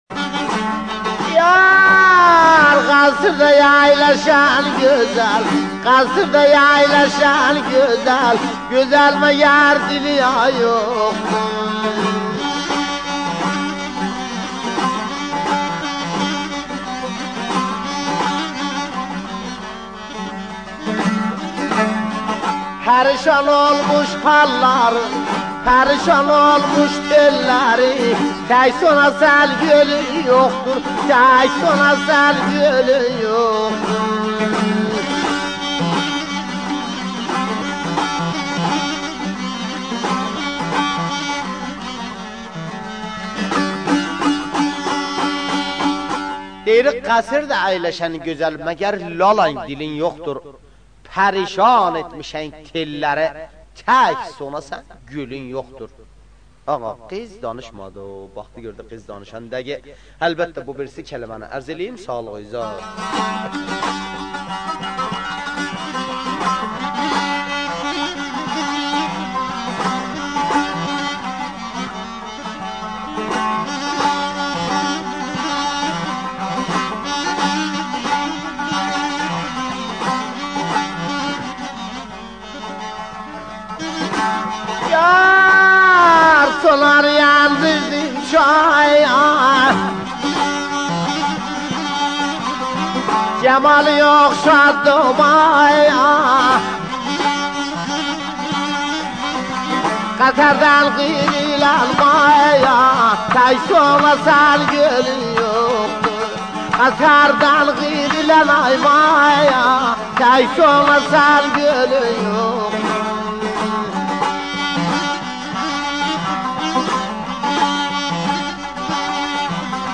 غمگین
ترکی همدان